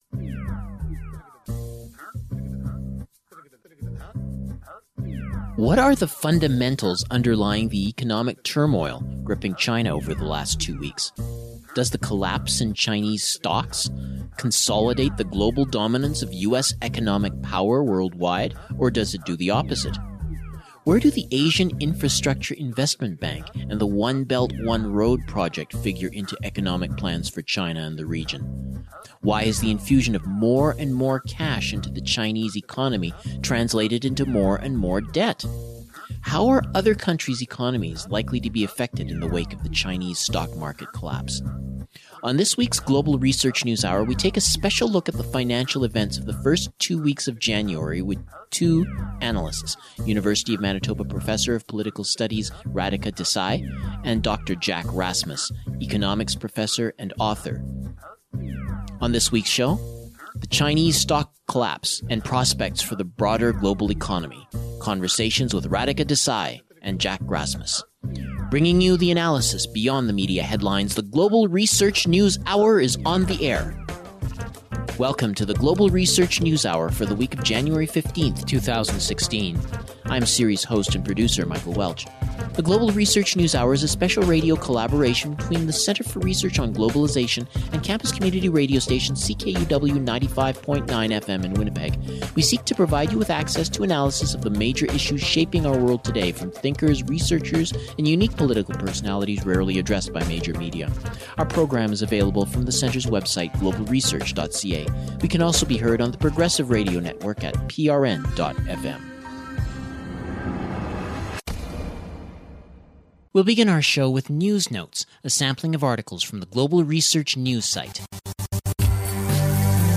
Conversations with two analysts about the Chinese economic situation